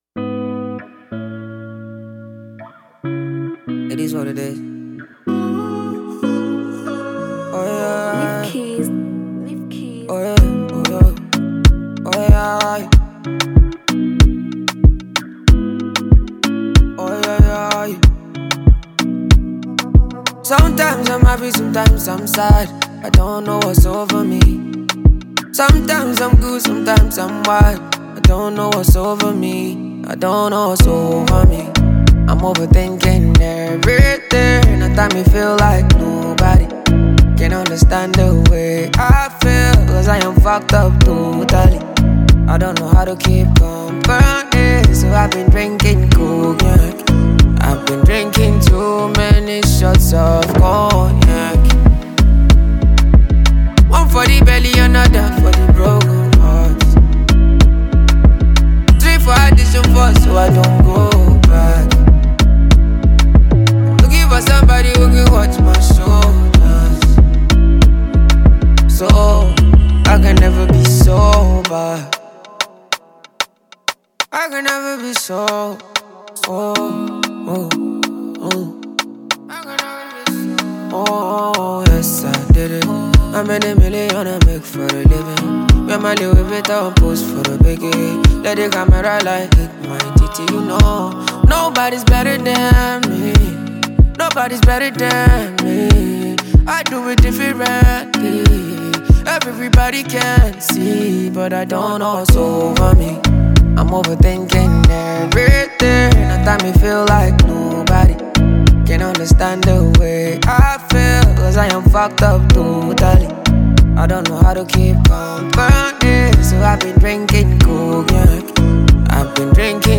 blends Afrobeat with contemporary sounds
With its high energy tempo and catchy sounds